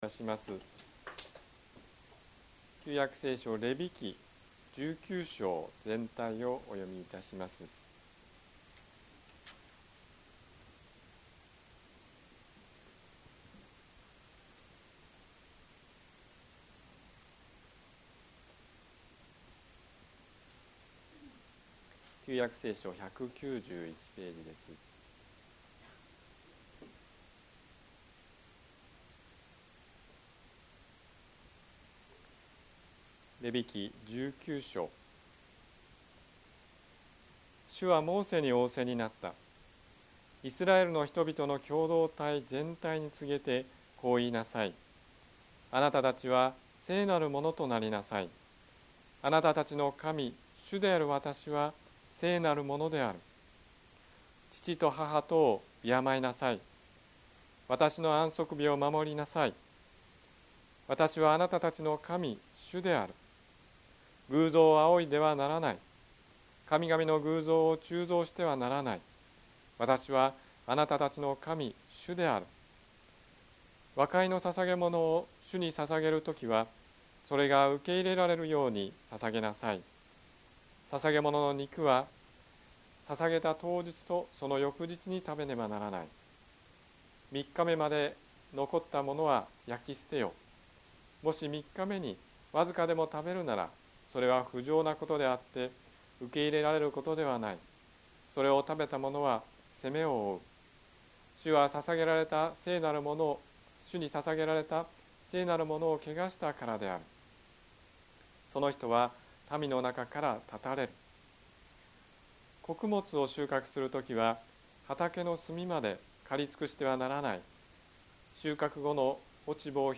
説教アーカイブ 日曜 夕方の礼拝